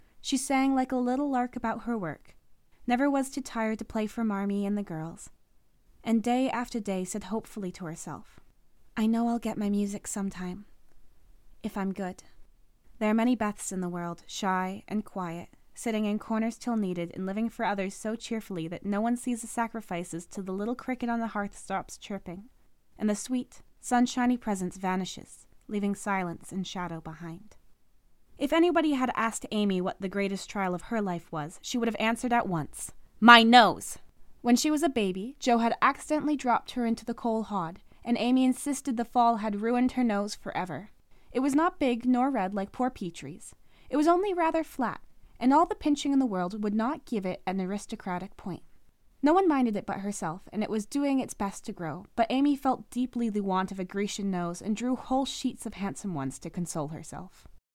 일본어 더빙, 원어민 녹음, 일본어
내래이션 전문 원어민 성우